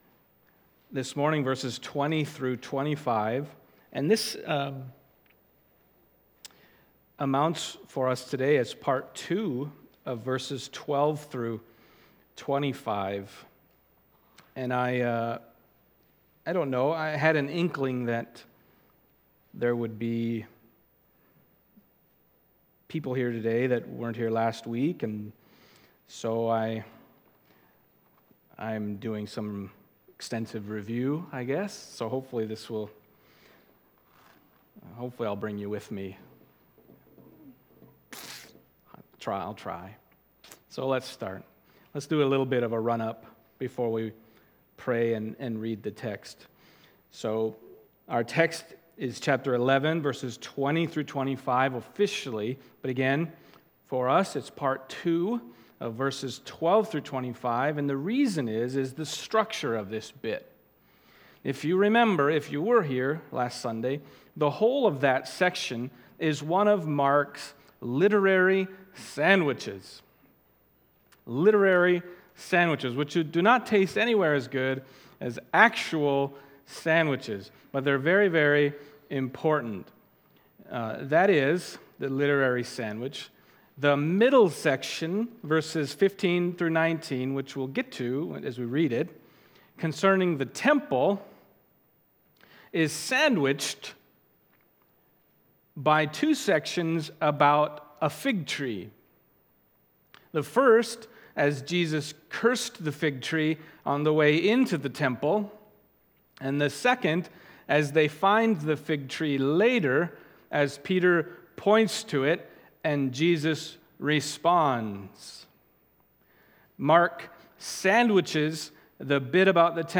Passage: Mark 11:20-25 Service Type: Sunday Morning Mark 11:20-25 « Cursing a Tree